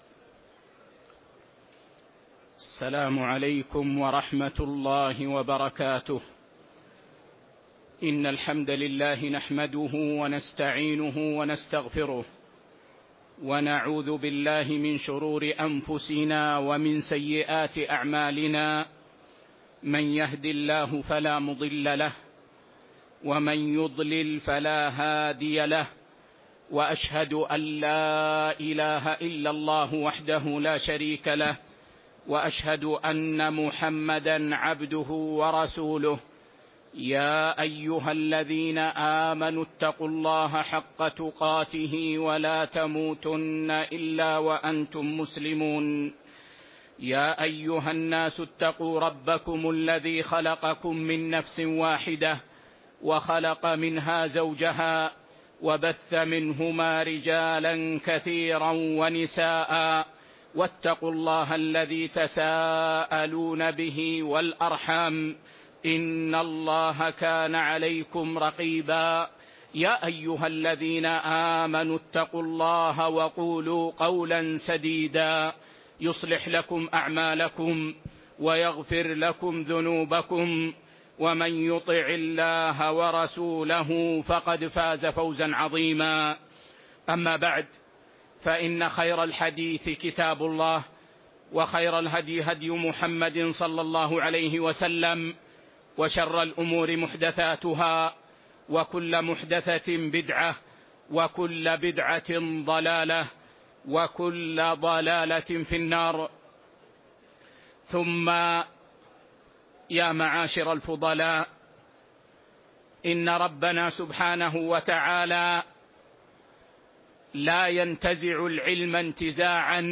شرح صحيح مسلم الدرس 15